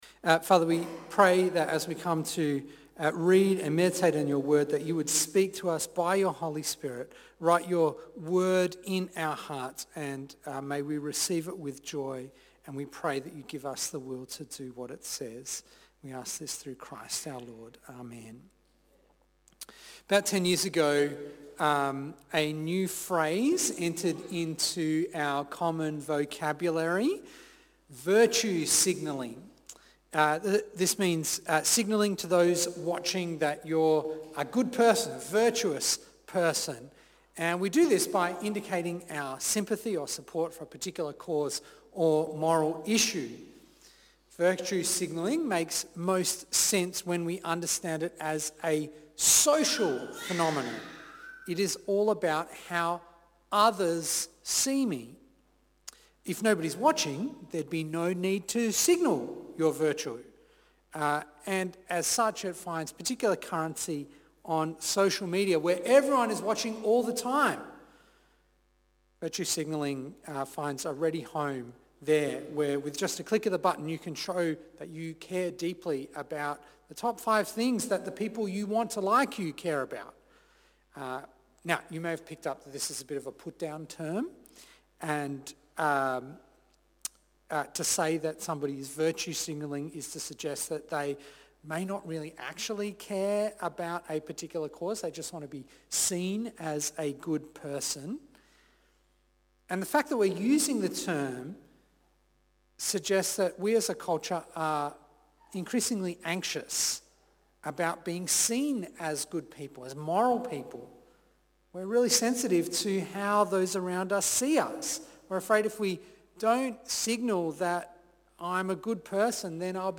Loss for Christ Preacher